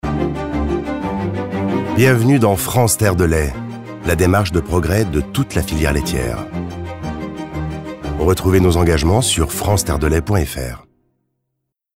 Pub télé Toupargel
Voix off
35 - 60 ans - Baryton-basse